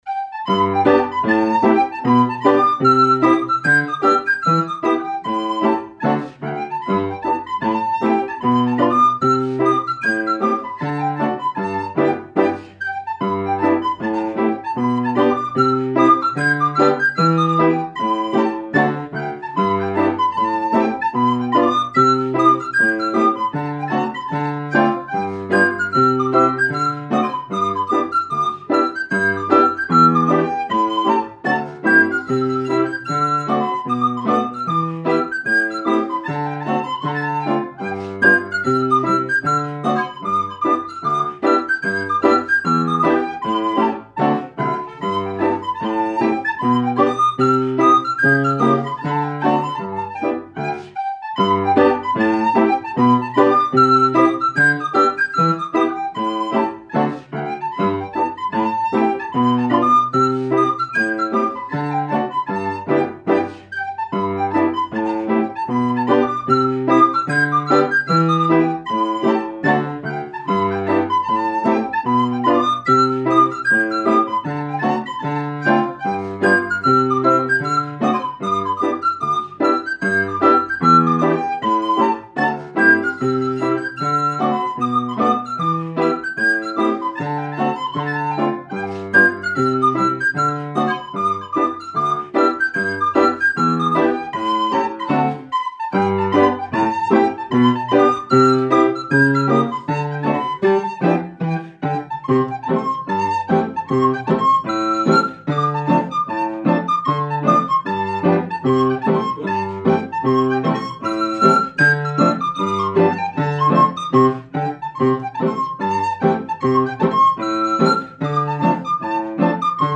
Scottish-Folk (9/24 - MP3, 4,66 MByte)